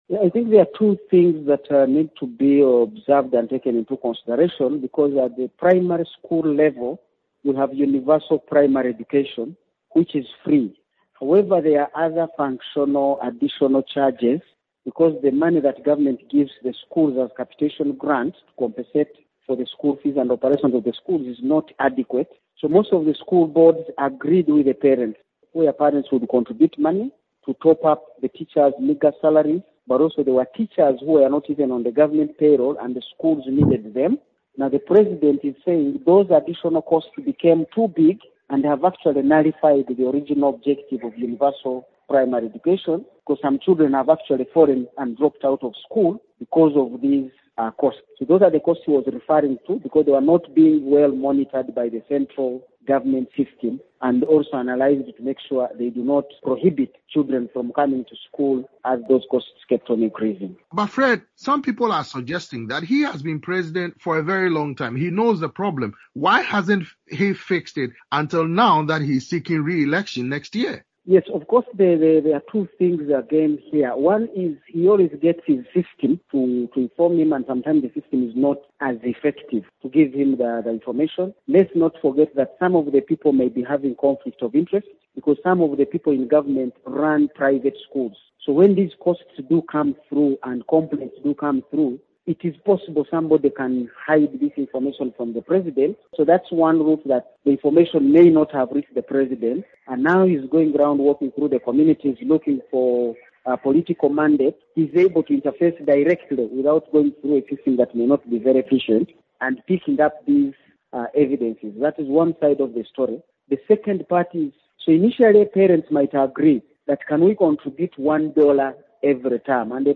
economist and political analyst